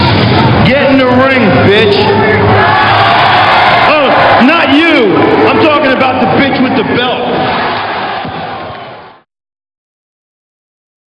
- This clip comes from ECW on TNN - [6.02.00]. Raven cuts a promo calling "the bitch" Justin Credible into the ring to fight him, not "the bitch" Francine. - (0:22)